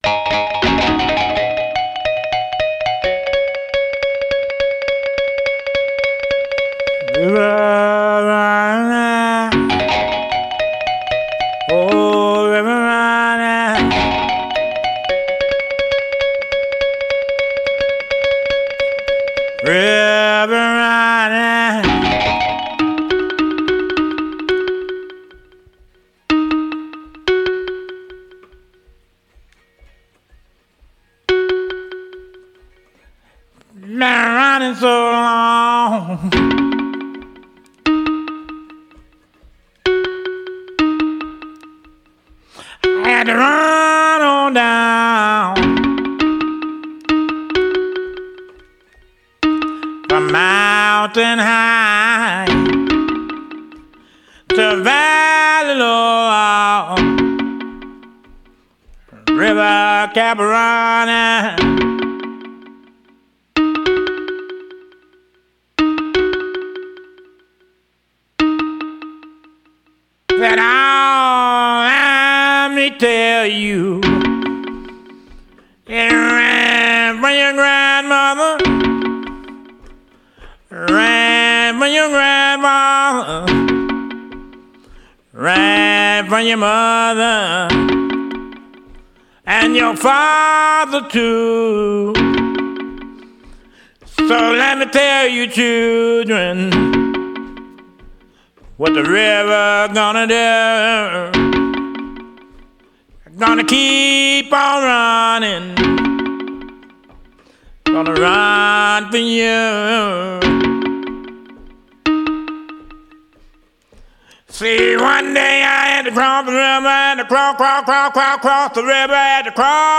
ソウル、ブルース、ジャズの濃厚なエッセンス！